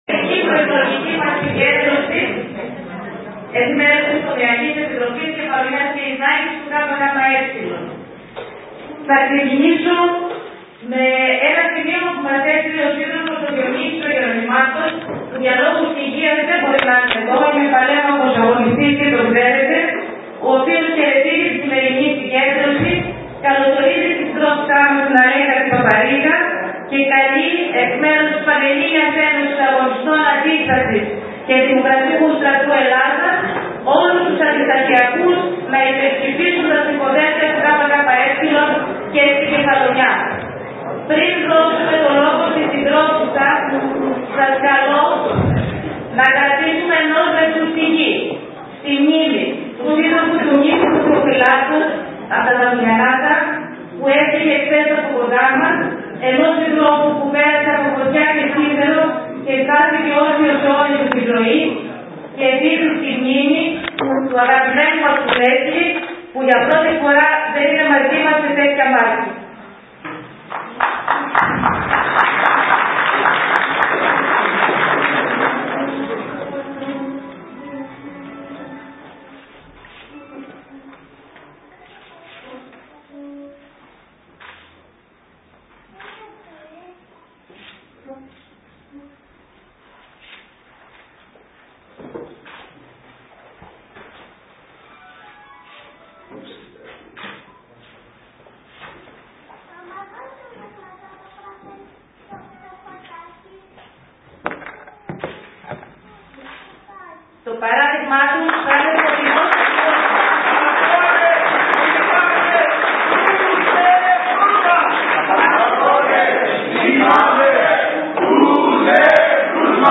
Μαζική και με αγωνιστικό παλμό ήταν η συγκέντρωση του ΚΚΕ στο Αργοστόλι, με ομιλήτρια την Αλέκα Παπαρήγα, μέλος της ΚΕ του ΚΚΕ και επικεφαλής του ψηφοδελτίου Επικρατείας του Κόμματος.
ΟΜΙΛΙΑ-ΑΛ_-ΠΑΠΑΡΗΓΑ.mp3